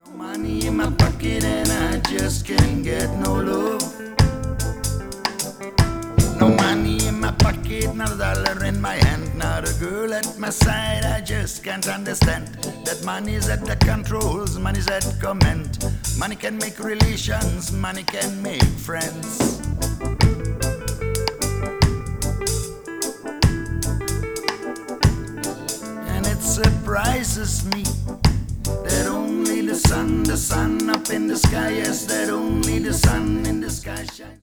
Cool reggae from the Netherlands